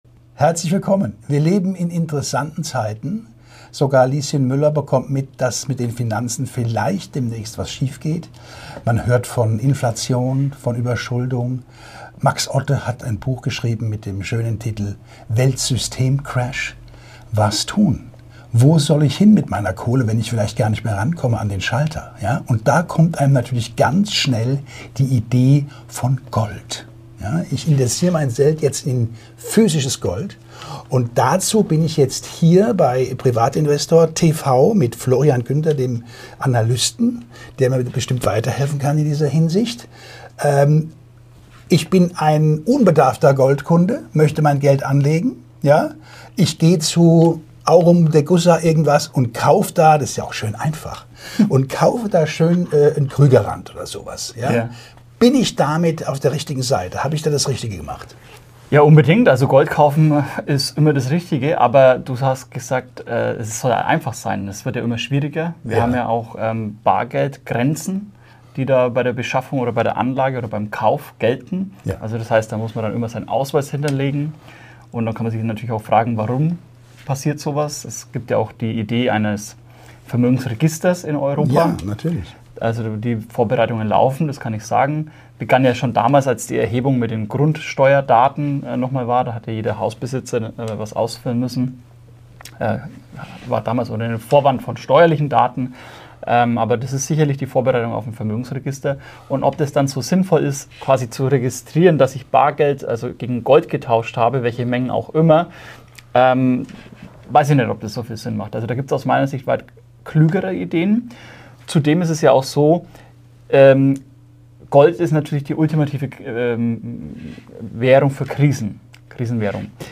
Ein informatives Interview über die Möglichkeiten der physischen Goldanlage außerhalb der EU und deren Vor- und Nachteile gege...